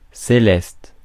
Ääntäminen
IPA: /se.lɛst/